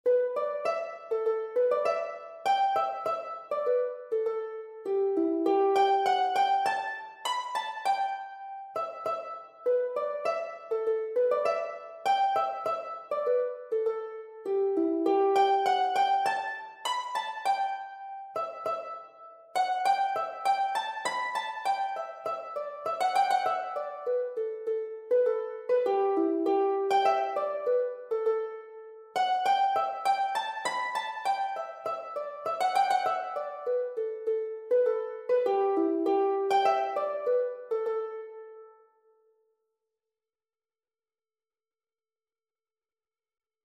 Harp  (View more Easy Harp Music)
Traditional (View more Traditional Harp Music)